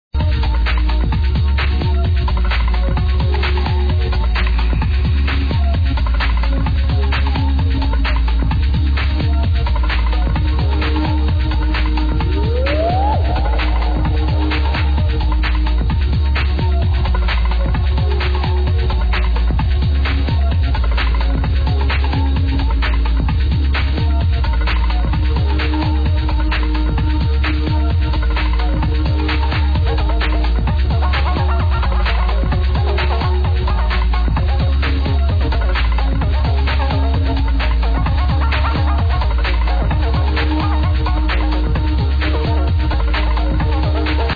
Smile Unknown uplifting breakbeat tune